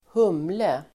Ladda ner uttalet
humle substantiv, hops Uttal: [²h'um:le] Böjningar: humlen Definition: slingerväxt som används vid öltillverkning (a climbing plant used in the manufacture of beer) hop substantiv, humle Grammatikkommentar: vanligen i plural